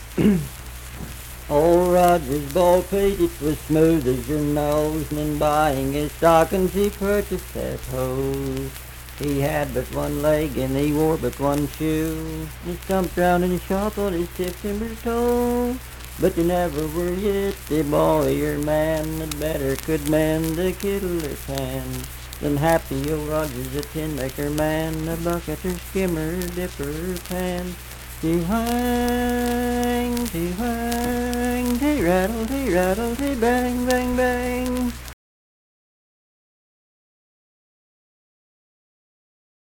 Unaccompanied vocal music
Verse-refrain 1(11).
Voice (sung)